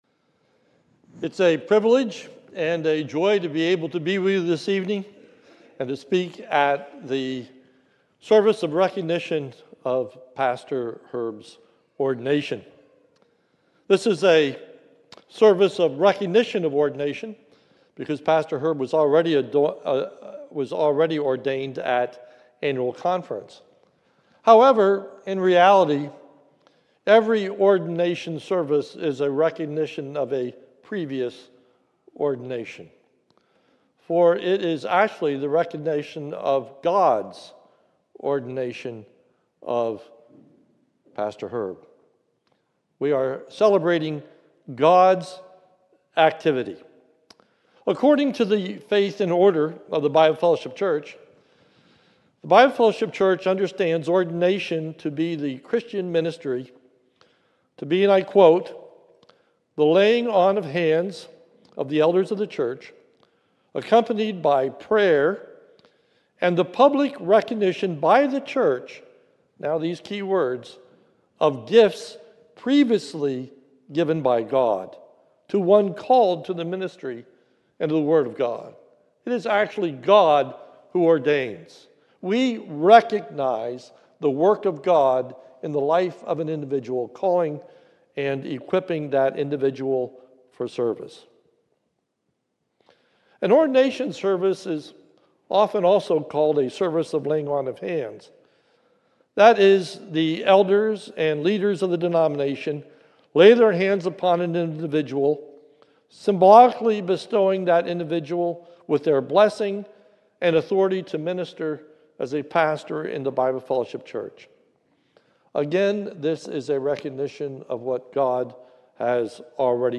It was recorded at the Lebanon Bible Fellowship Church in Lebanon, PA during the evening service on 9/7/2025.